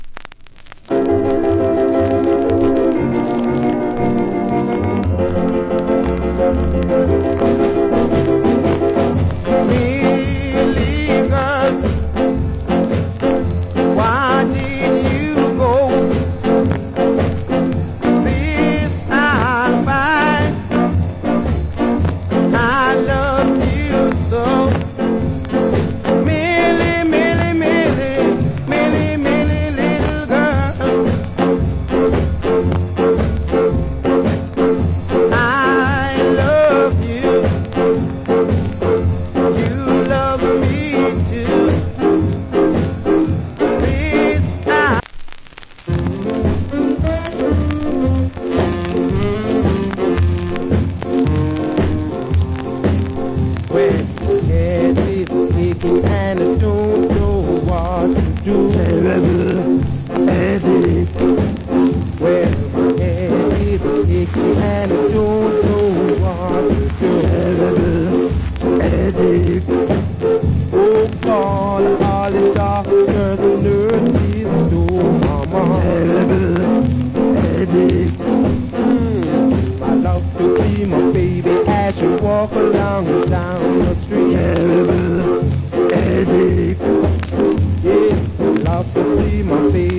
Nice Ska Vocal.
/ Good Ska Vocal.